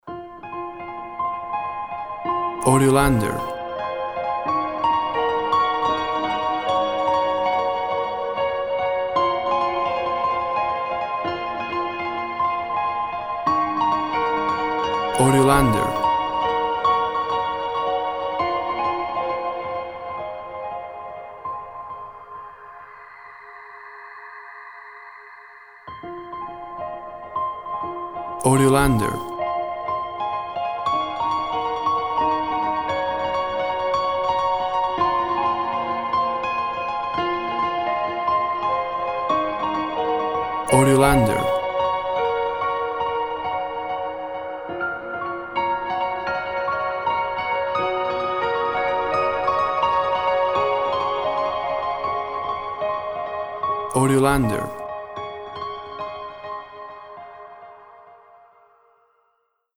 Reverb piano for seaside or space.
WAV Sample Rate 24-Bit Stereo, 44.1 kHz
Tempo (BPM) 80